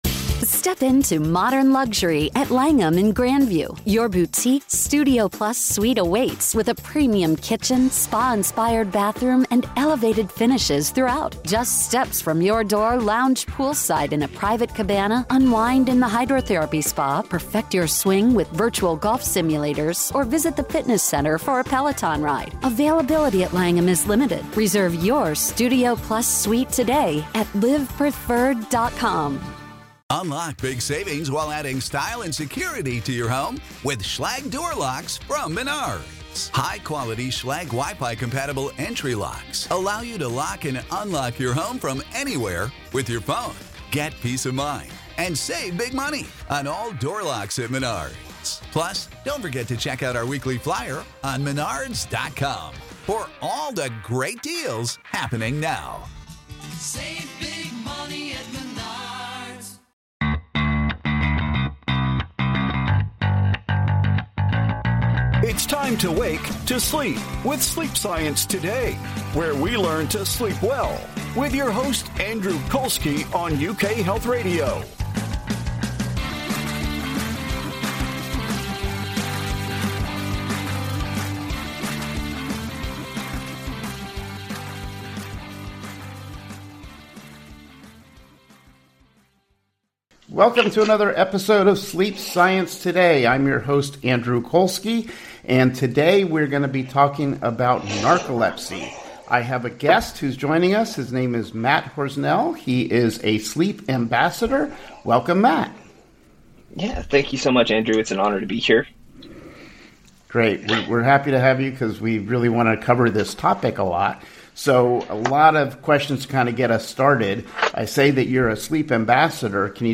Doctors have learned that restful sleep is critical for your physical and mental health. You will hear from renowned sleep experts as they share the latest information about how to sleep better with science.